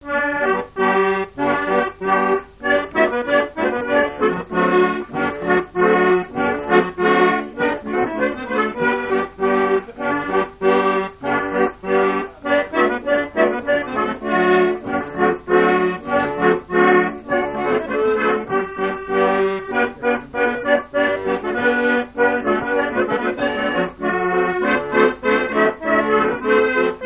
Localisation Rochetrejoux
gestuel : danse
Pièce musicale inédite